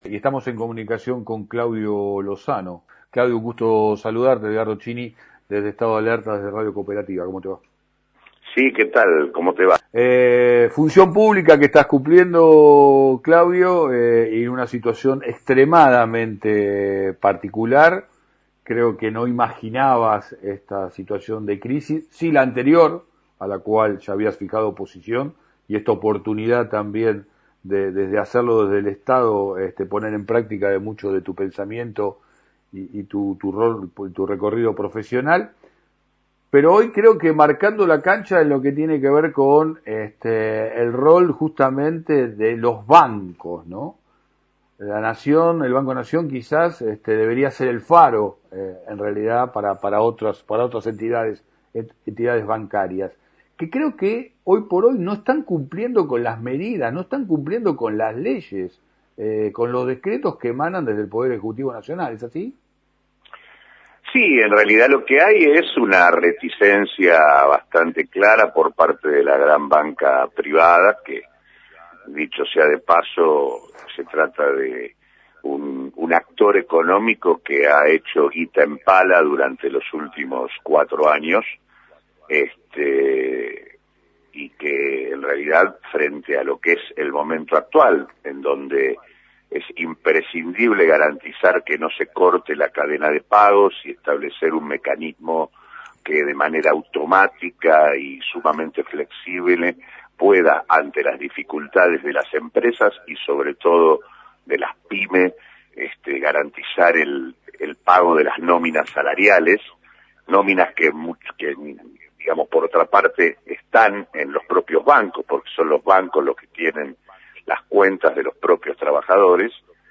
Entrevista a Claudio Lozano – Director del Banco Nación